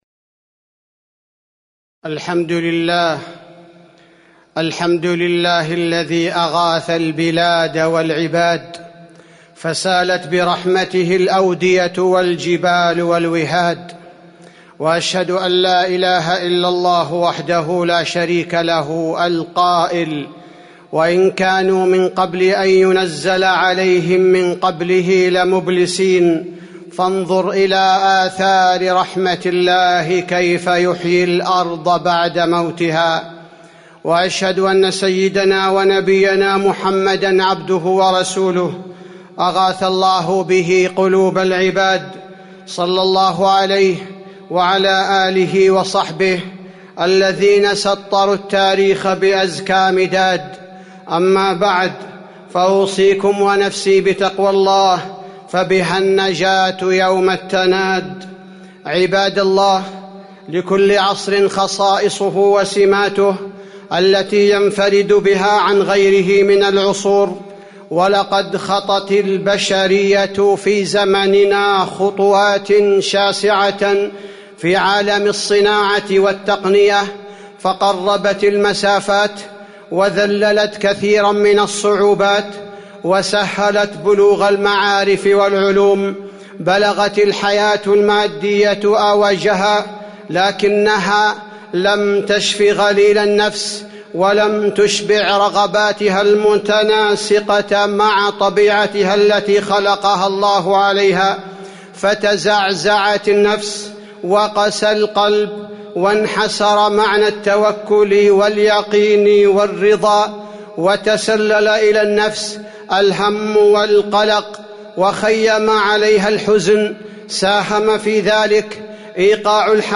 تاريخ النشر ٤ جمادى الآخرة ١٤٤٣ هـ المكان: المسجد النبوي الشيخ: فضيلة الشيخ عبدالباري الثبيتي فضيلة الشيخ عبدالباري الثبيتي اللهم إني أعوذ بك من الهم والحزن